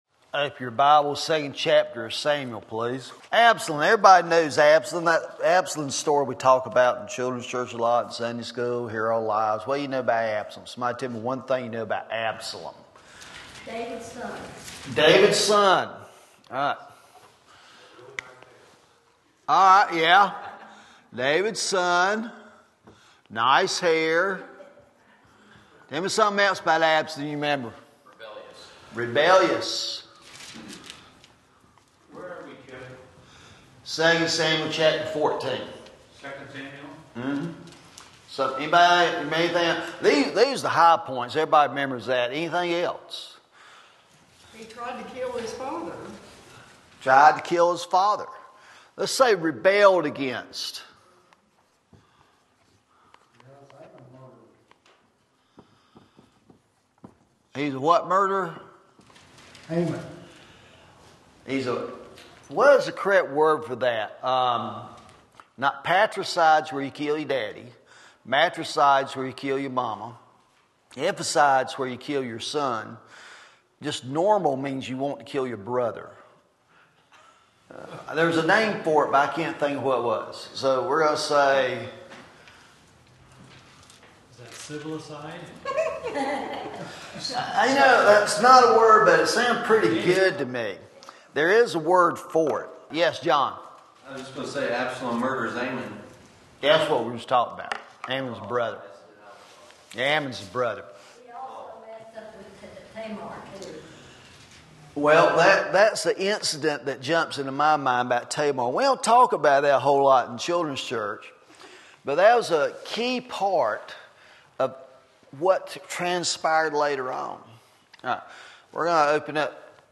In this lesson, we examine the contrast painted between David's love and Absalom's selfishness.